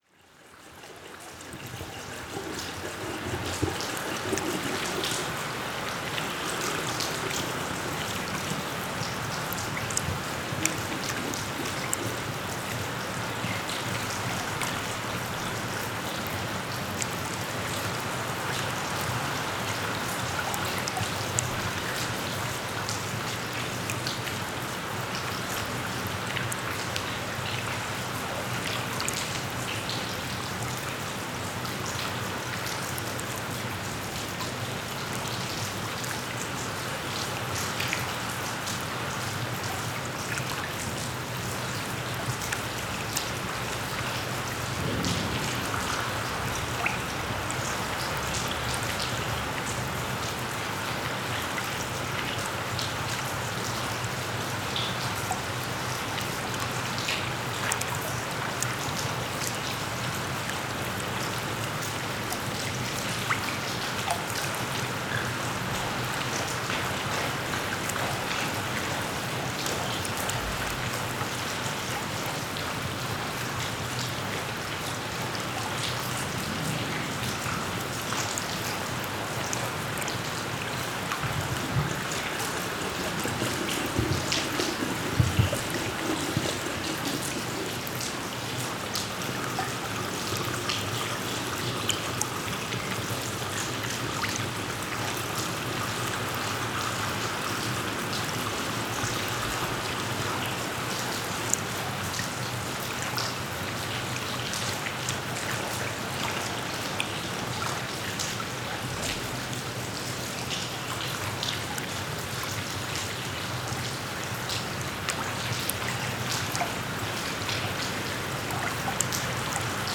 NODAR.00521 – Lordosa: Escoamento de água de lavadouro em Fermentelos
Paisagem sonora de escoamento de água de lavadouro comunitário em Fermentelos, Lordosa a 17 Fevereiro 2016.